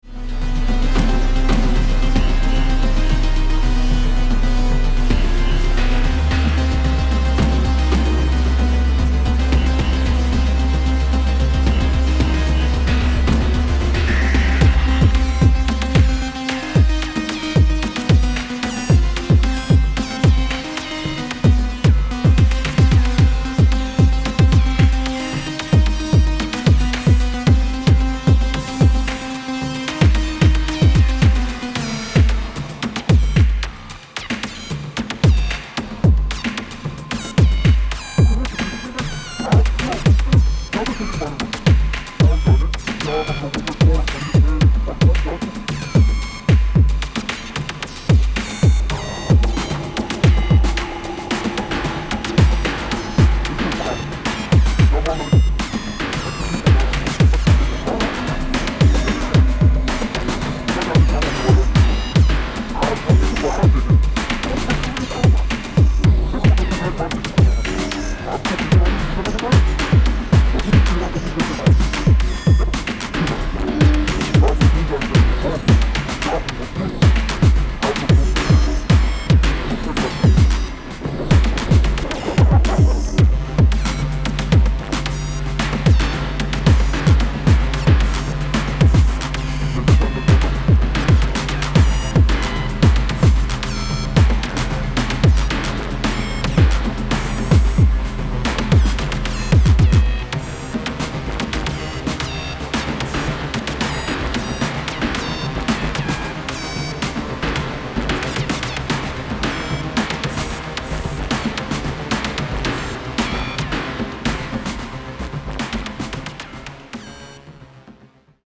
Electronix Bass Wave